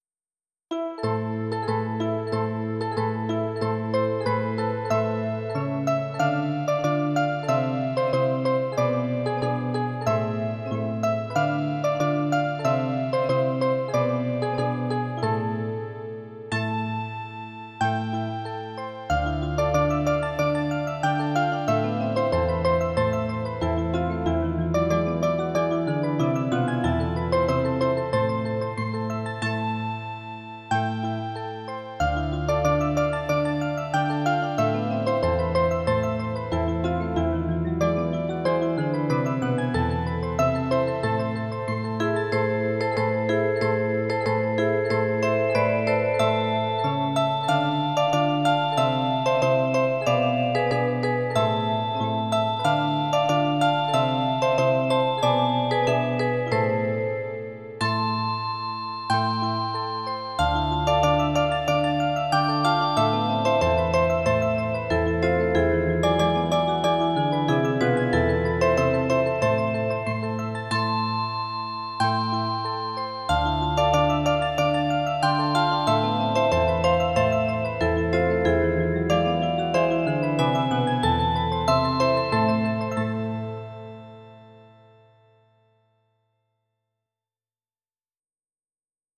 folk song Switzerland